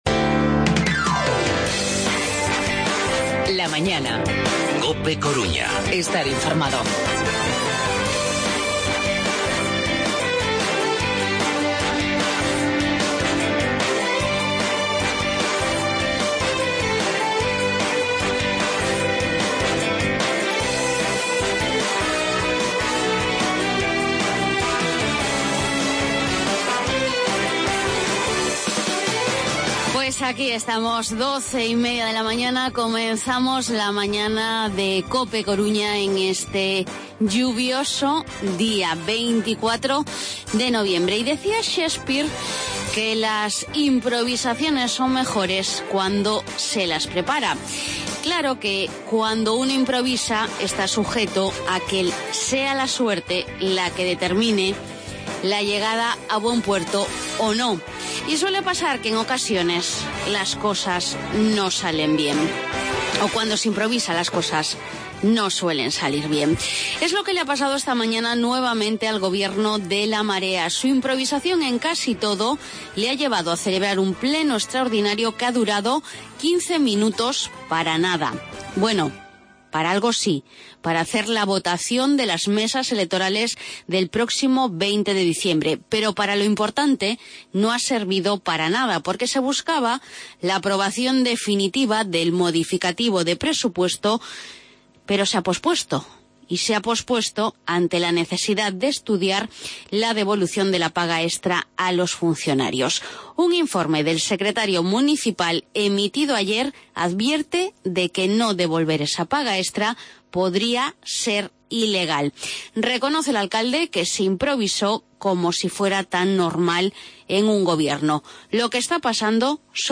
AUDIO: Entrevista delegado de la Xunta en A Coruña.